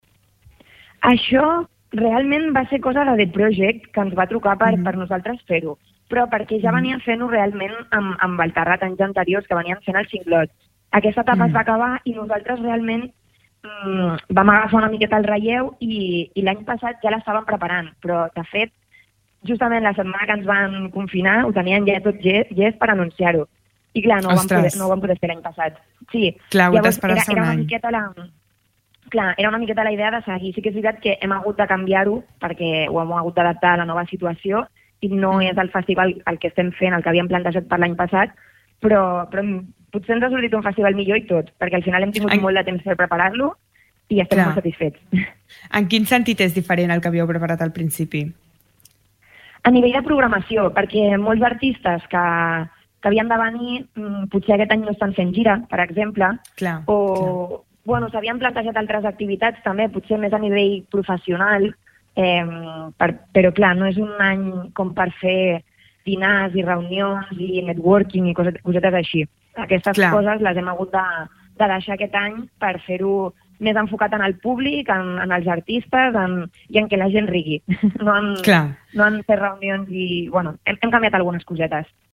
Entrevistes SupermatíGeneralSant Feliu de Guíxols